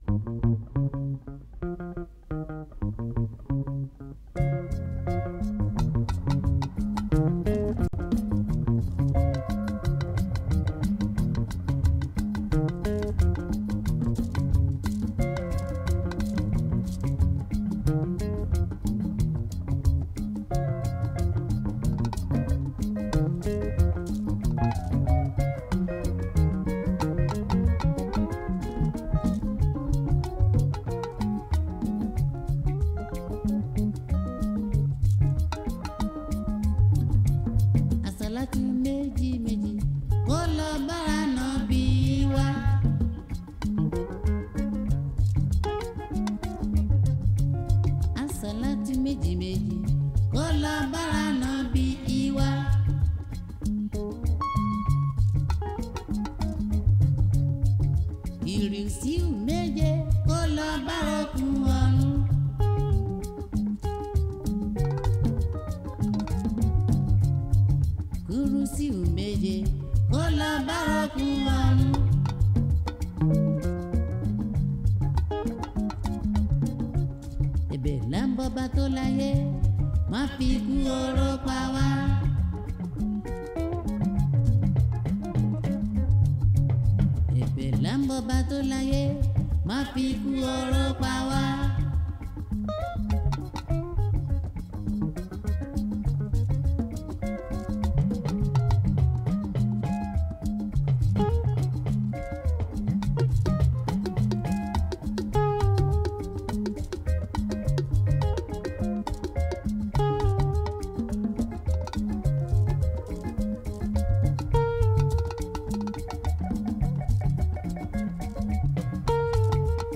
February 14, 2025 Publisher 01 Gospel 0